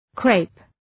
Shkrimi fonetik {kreıp}